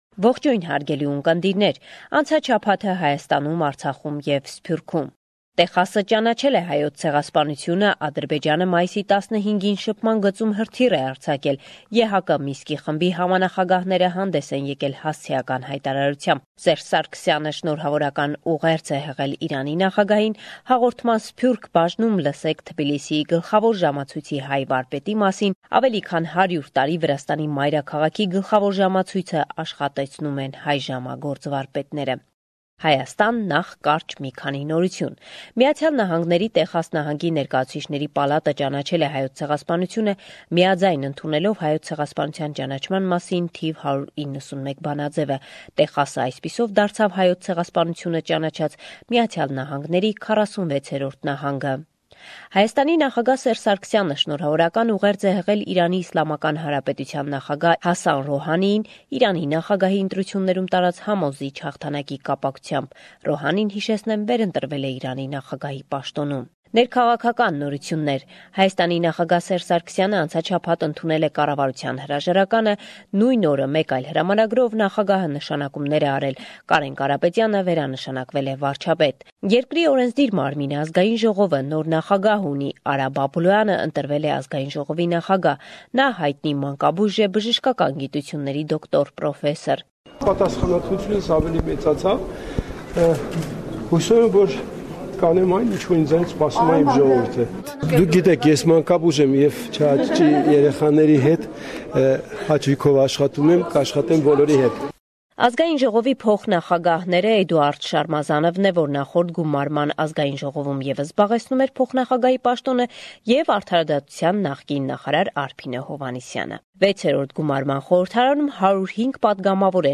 Latest News – 23 May 2017